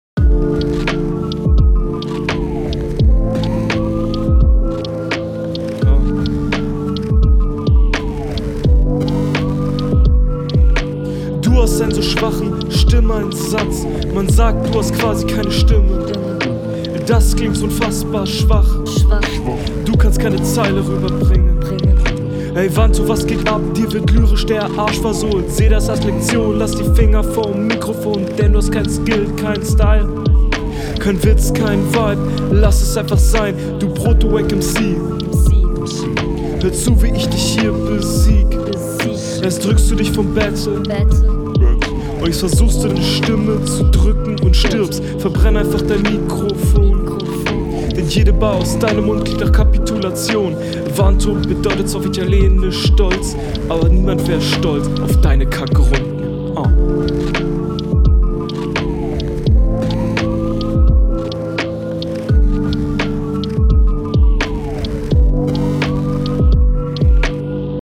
beat find ich interessant, hat irgendwie was. kommst stimmlich auch wieder nice, flowlich ist das …
Auf diesem langsamen Beat gefällt mir dein charakteristischer Flow nicht mehr ganz so gut. z.B.: …